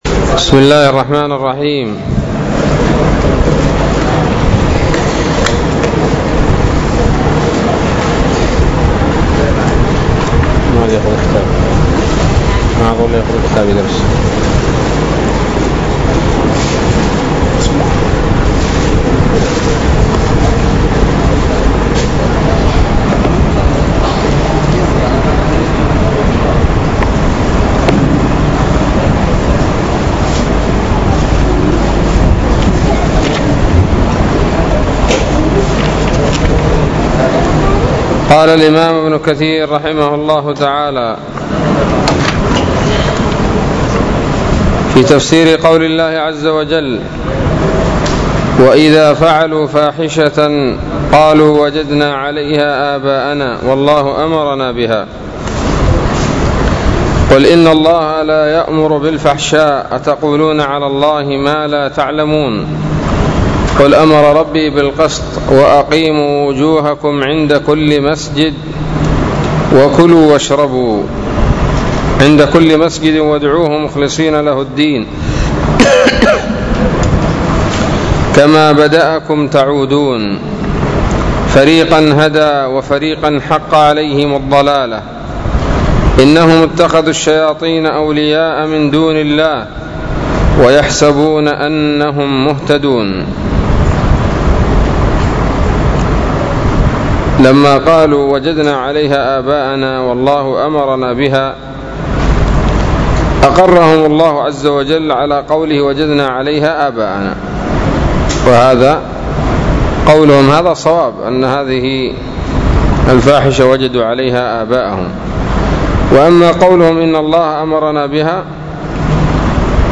الدرس العاشر من سورة الأعراف من تفسير ابن كثير رحمه الله تعالى